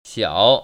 [xiăo] 샤오